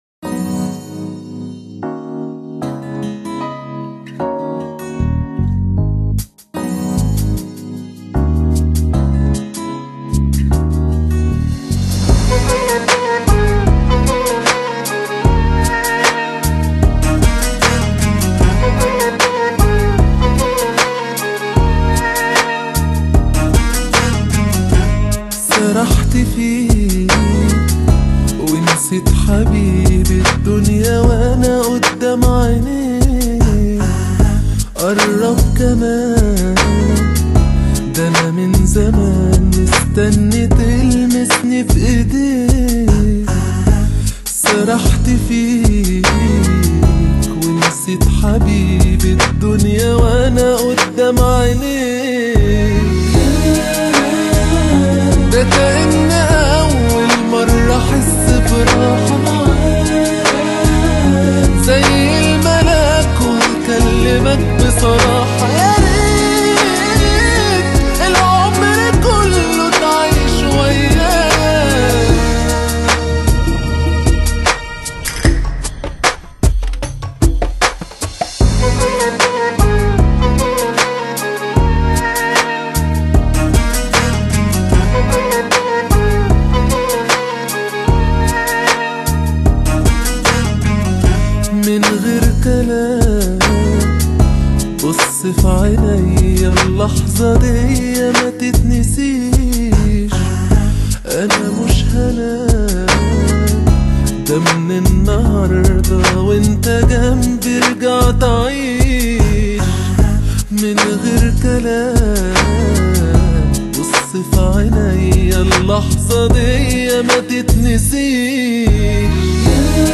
Genre: Lo-Fi, Chillout
CHILL OUT+阿拉伯风情